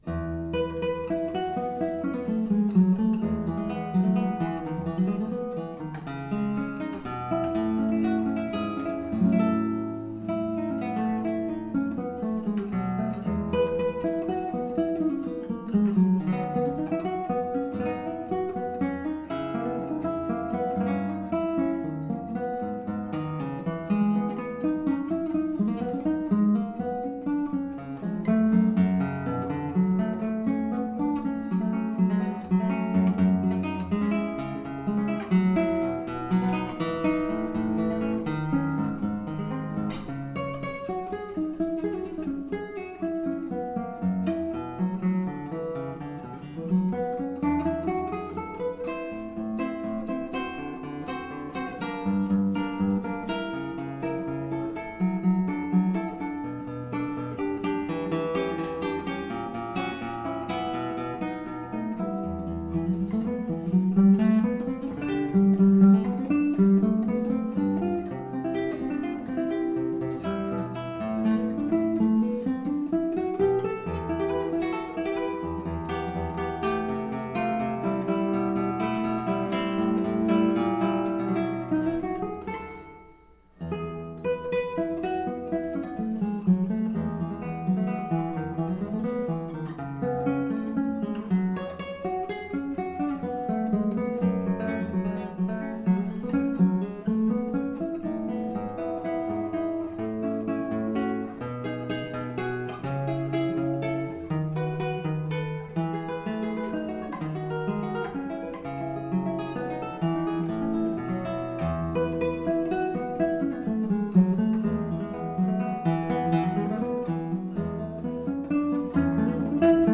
Specify if to play music on hold (MOH).
The file format should be PCM 8kHz 16 bit mono or as described at the API_PlaySound.